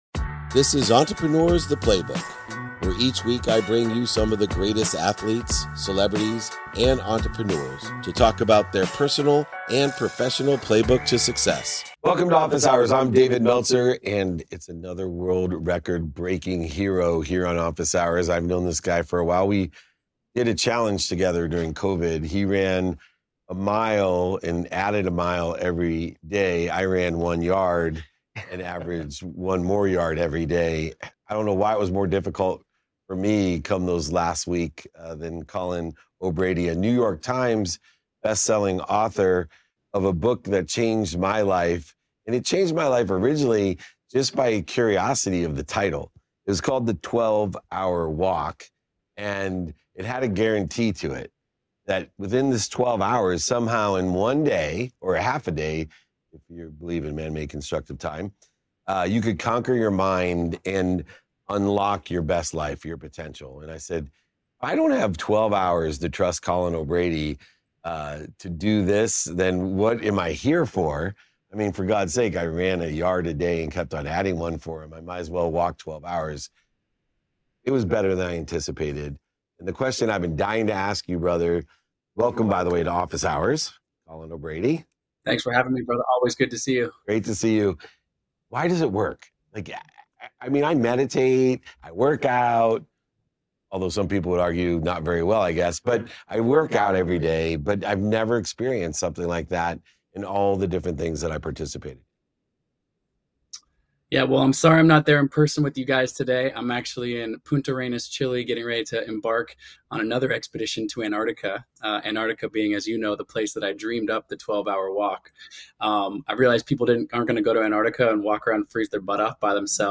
In today's episode, I chat with adventurer and author Colin O'Brady, whose experiences stretch from icy Antarctic treks to the endurance of the 12-Hour Walk. We discuss how challenges, both physical and mental, act as gateways to discovering our potential. Colin shares insights on how embracing our lowest moments can set the stage for our greatest achievements, emphasizing the transformative power of solitude and self-reflection in unlocking profound personal growth.